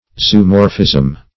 Zoomorphism \Zo`o*mor"phism\, n.